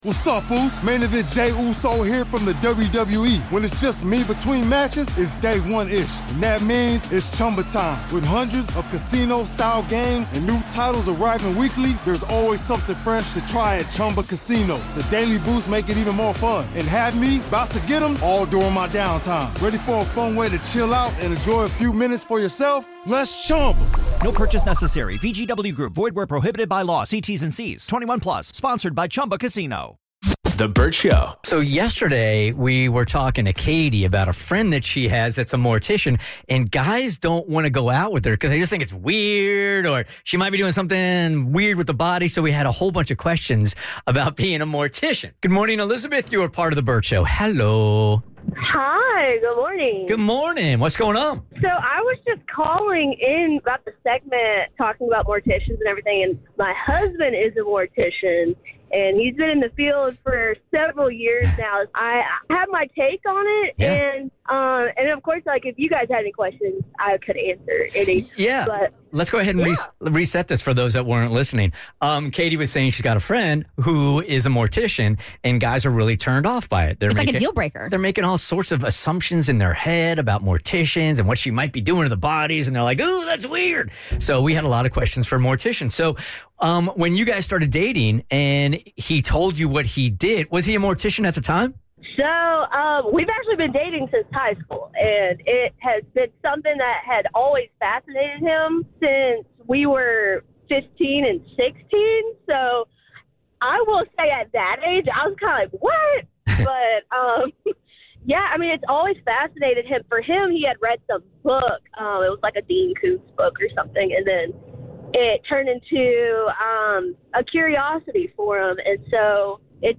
We’ve got a mortician’s wife on the line!